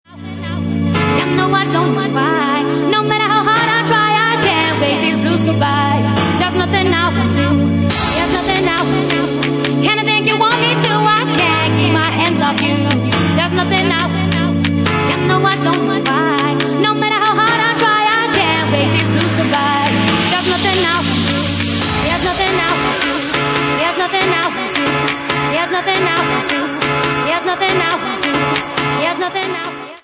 Bassline House